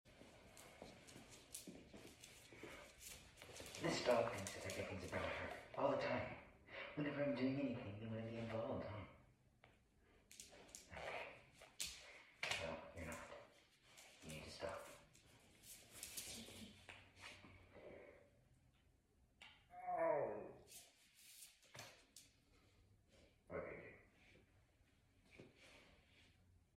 the noise my dog makes sound effects free download